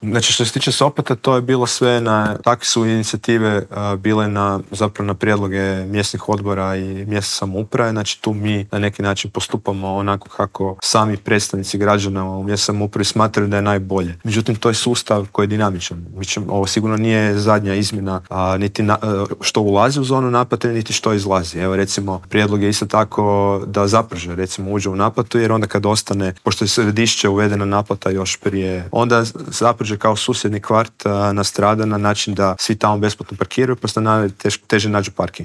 ZAGREB - Blokovsko parkiranje, nikad više automobila u metropoli - najavljena je izgradnja nove infrastrukture - gradit će se Jarunski most, proširiti tramvajska mreža na Sarajevskoj cesti - što nas sve čeka u idućim godinama u metropoli u Intervjuu tjedna Media servisa rekao nam je zagrebački gradonačelnik Tomislav Tomašević - otkrio je i kada možemo očekivati završetak novog maksimirskog stadiona.